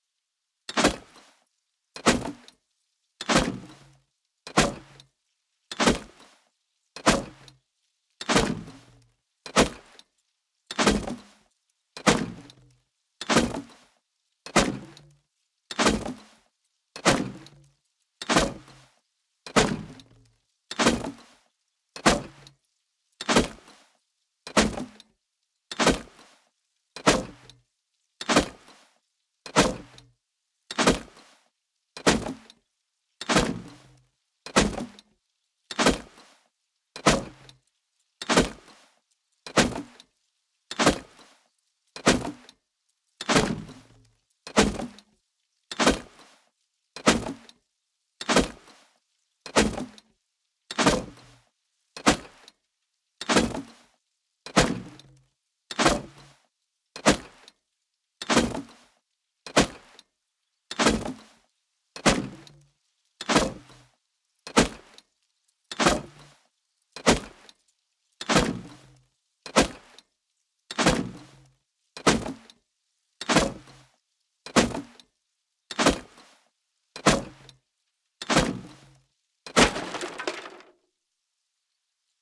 Salvaged Axe On Wood Wall